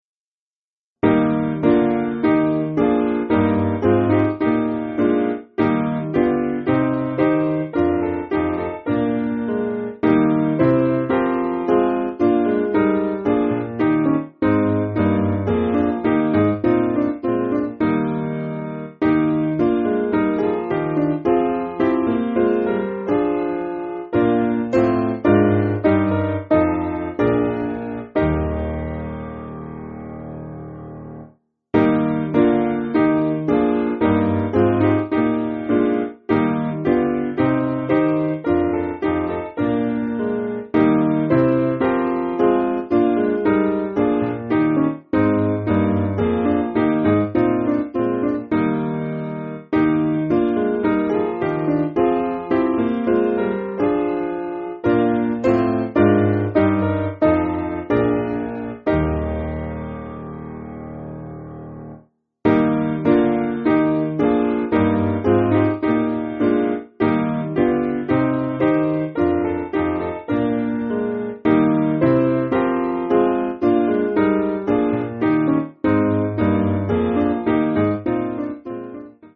Simple Piano
(CM)   3/Eb